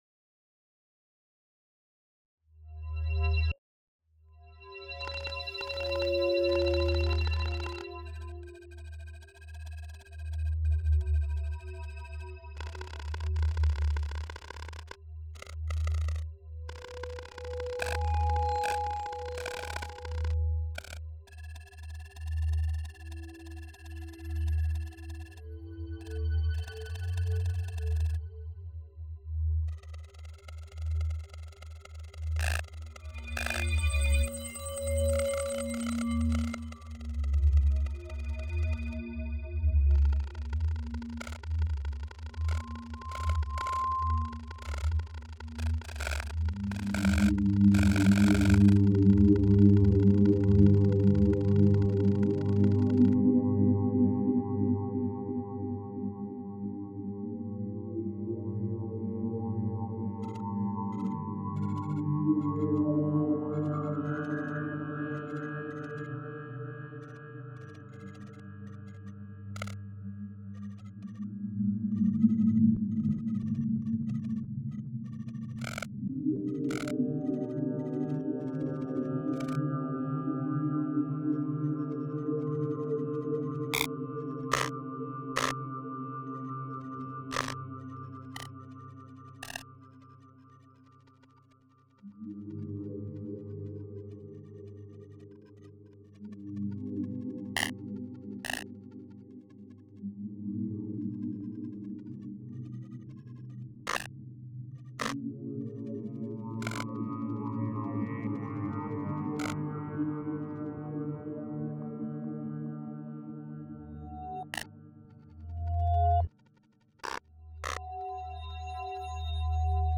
By the end of the two-week workshop, the participants had produced at least one complete work of sound, with some creating up to 4.